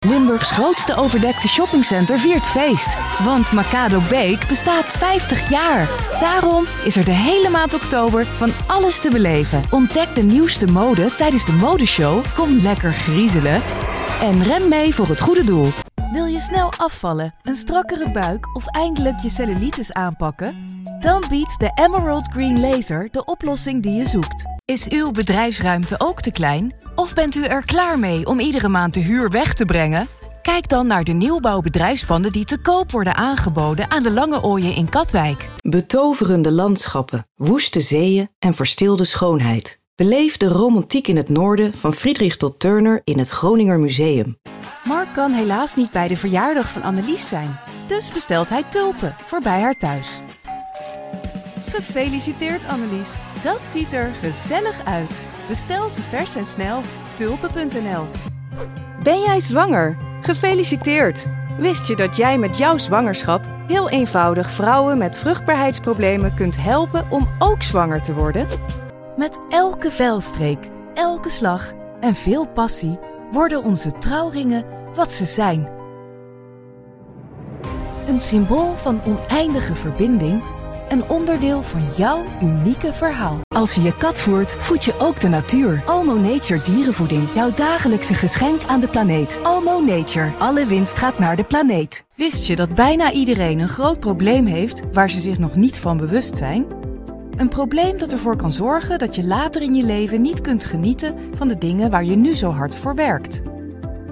Natuurlijk, Speels, Toegankelijk, Veelzijdig, Vriendelijk
Commercieel